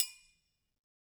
Percussion
Triangle6-HitFM_v2_rr1_Sum.wav